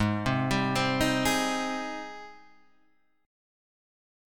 G# Major 7th